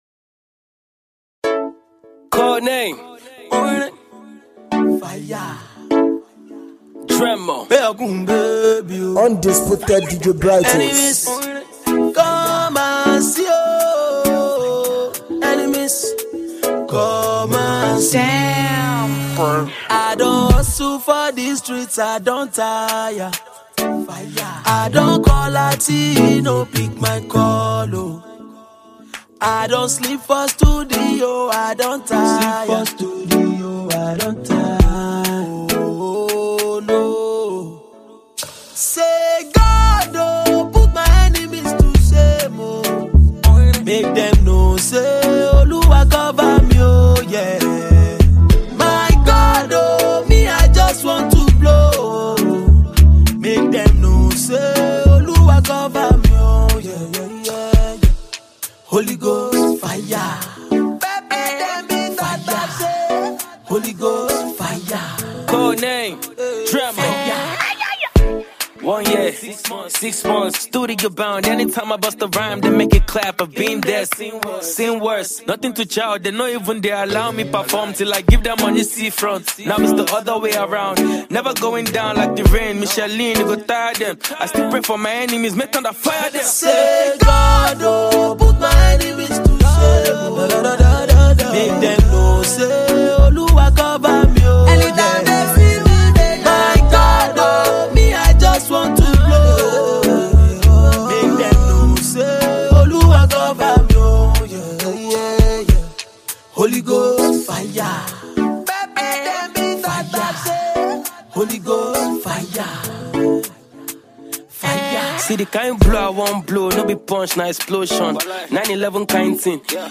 This is a compilation of almost the best covers